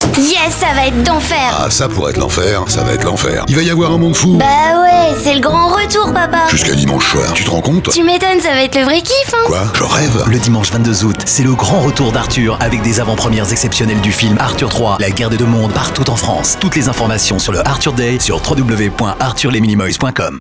ARTHUR 3 LA GUERRE DES DEUX MONDES – PUB RADIO – VOIX JEUNE